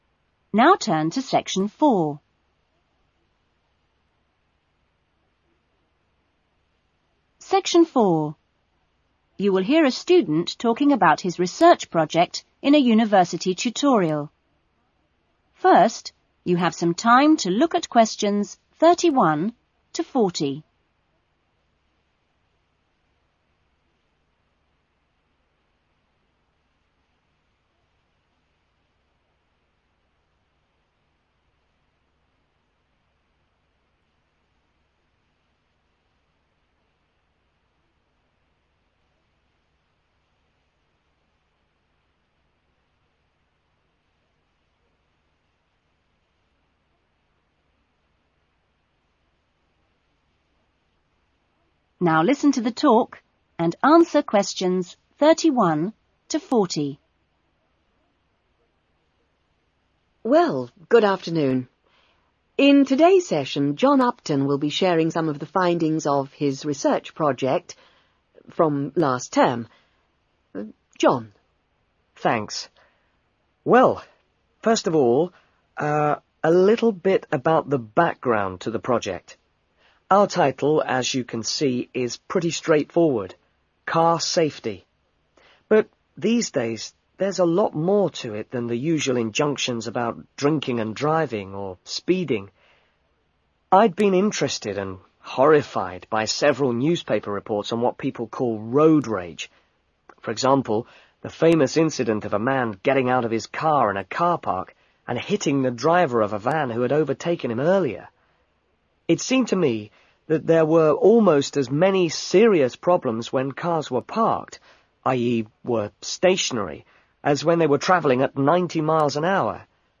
Trong phần Section 4 của bài IELTS Listening topic Education, bạn sẽ thường gặp các bài giảng hoặc bài thuyết trình mang tính học thuật với mức độ khó cao hơn các phần trước.